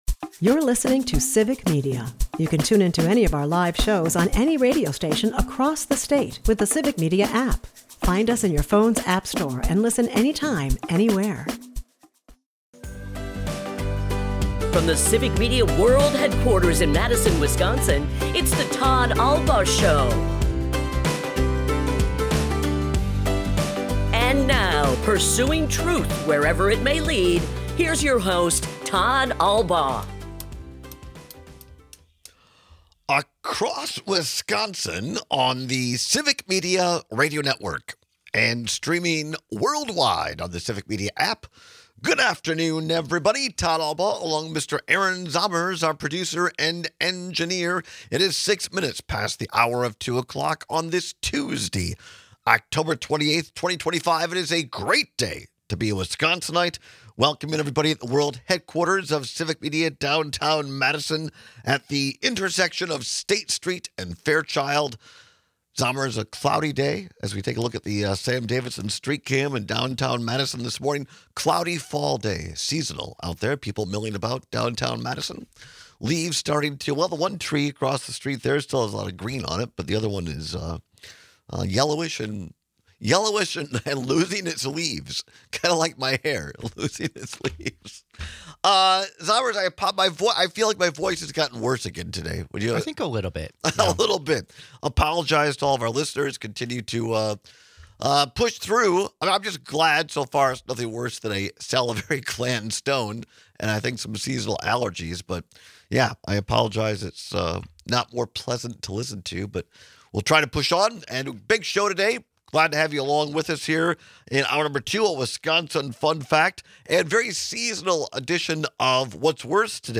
airs live Monday through Friday from 2-4 pm across Wisconsin